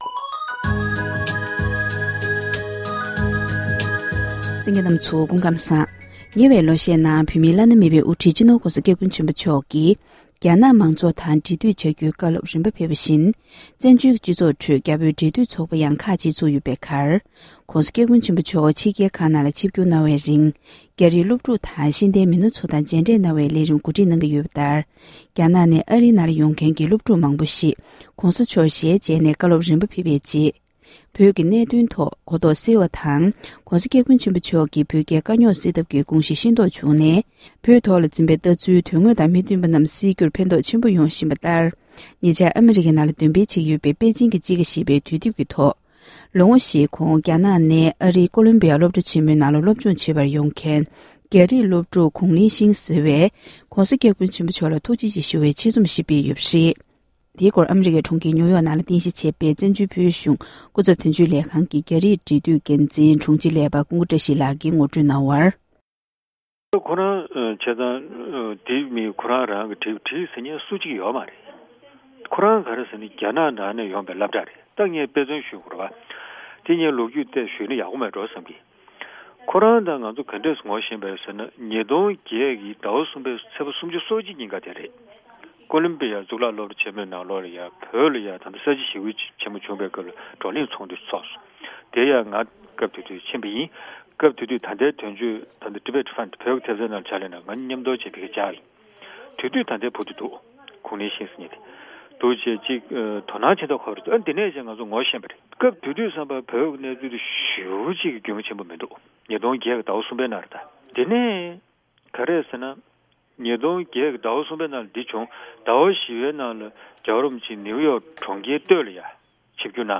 བཀའ་འདྲི་ཞུས་པ་ཞིག་གསན་རོགས༎